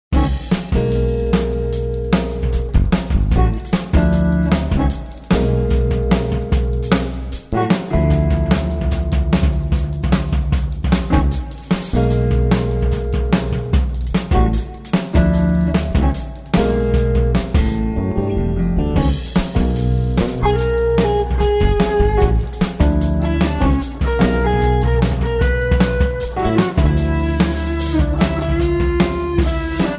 Guitars
Bass
Drums